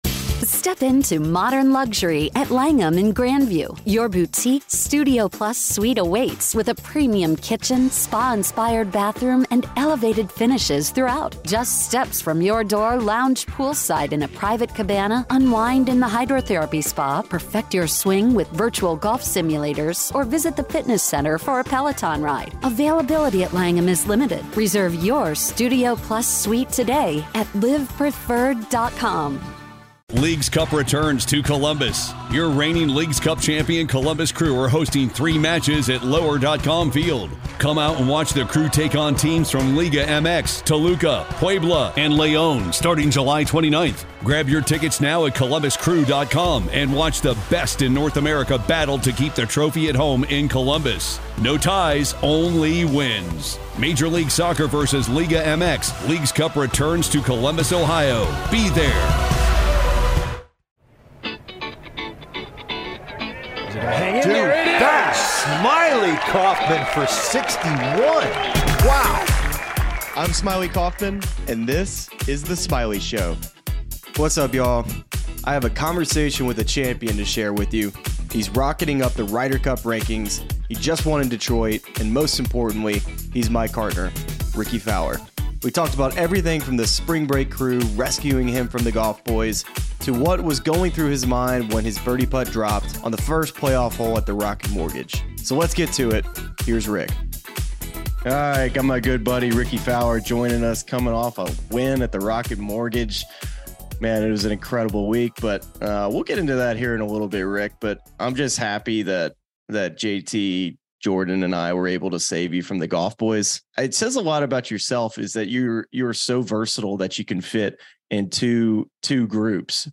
Smylie is joined by his "cartner," fellow 2016 spring breaker, and good friend Rickie Fowler to recap Rick's first win on PGA Tour since 2019. Rickie explains the origin story behind his bold clothing choices, goes into detail on the swing changes he's made with Butch Harmon, and reveals the nerves he felt before the final round of the 2023 Rocket Mortgage Classic.